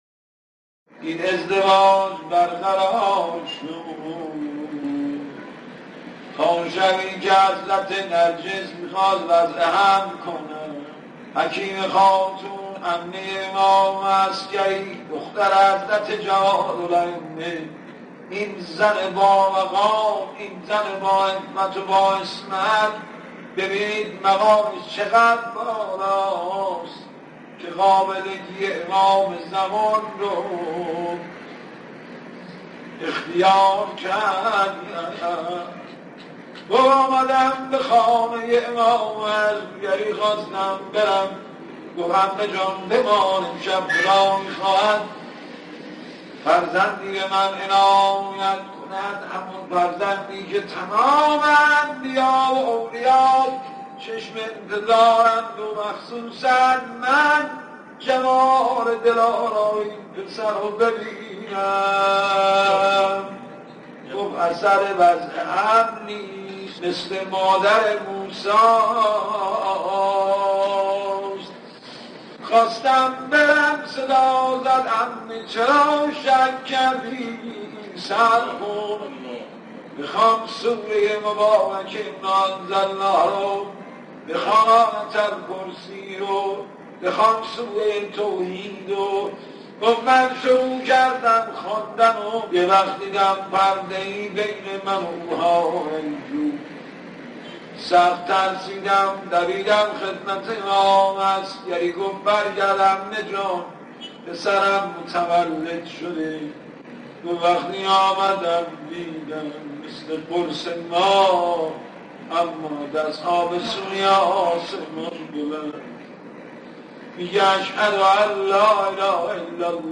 مدح - به دنیا امدن امام رضا علیه السلام